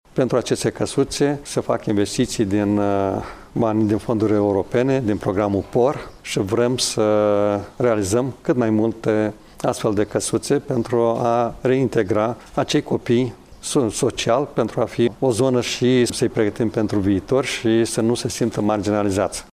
Preşedintele Consiliului Judeţean Iaşi, Maricel Popa, a precizat că în fiecare casă vor fi cazaţi copii cu vâste între 8 şi 14 ani.